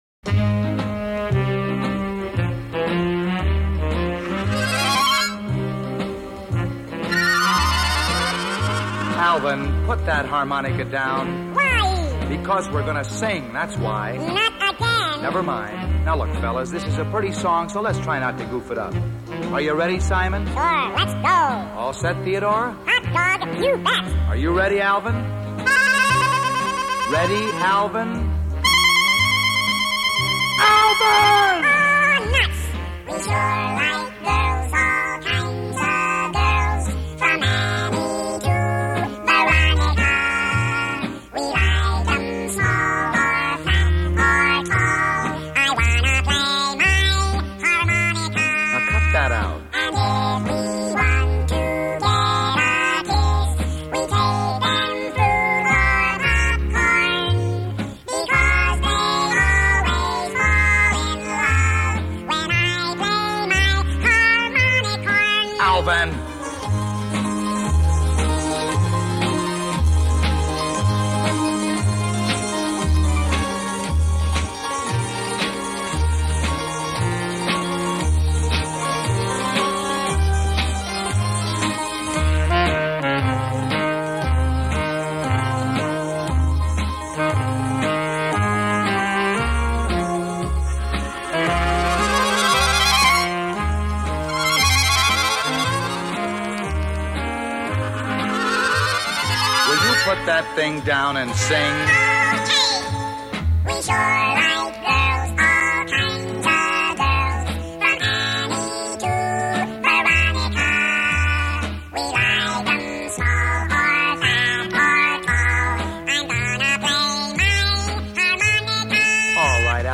The production values are absolutely flawless.